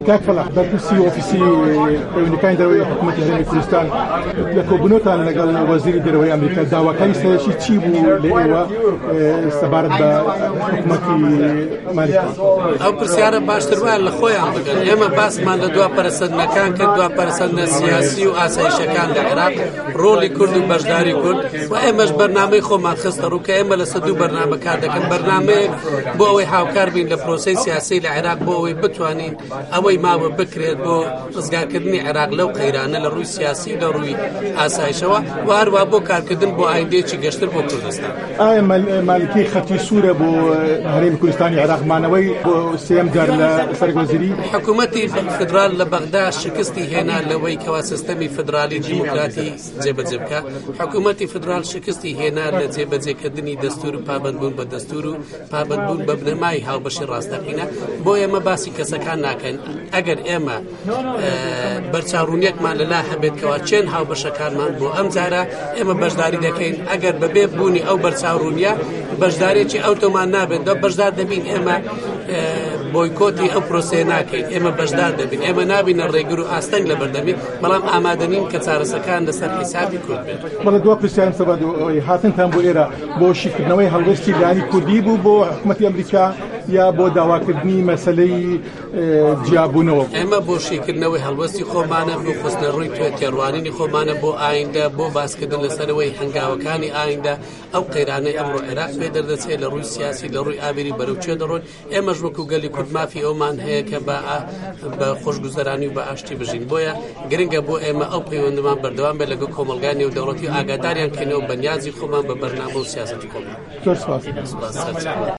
درێژه‌ی وتووێژه‌که‌‌ له‌م فایله‌ ده‌نگیـیه‌دایه‌.
وتووێژی فه‌لاح مسته‌فا